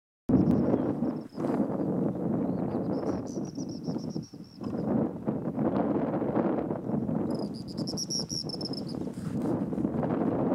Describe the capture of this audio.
Location or protected area: Parque Nacional Sierra de las Quijadas Condition: Wild Certainty: Photographed, Recorded vocal